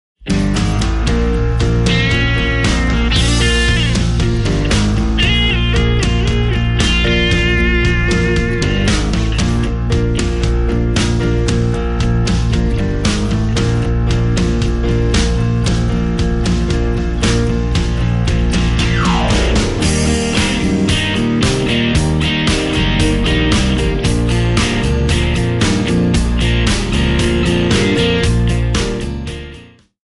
Eb
MPEG 1 Layer 3 (Stereo)
Backing track Karaoke
Country, Duets, 2000s